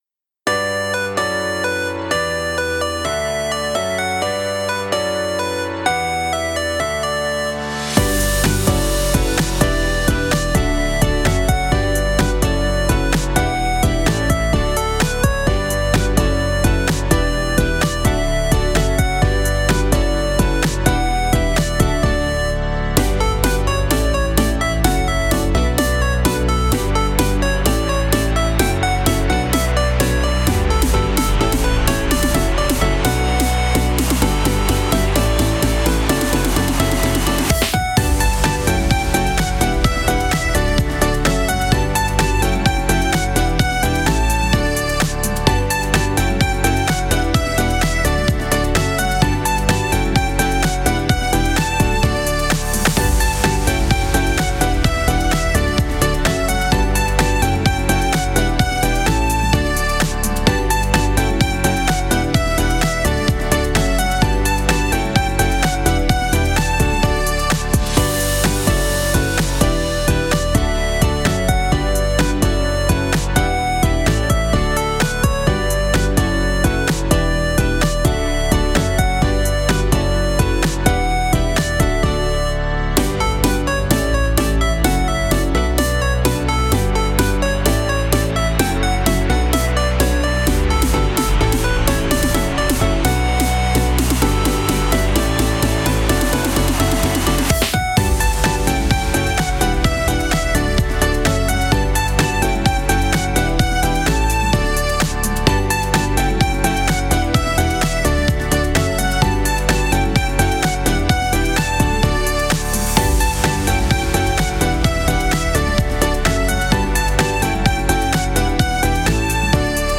かわいい, ゆったり, オープニング, コミカル, 日常, 明るい
かわいいが詰まったゆったり日常系BGMです。駅で待ち合わせしてるときのワクワク感を表現してみました！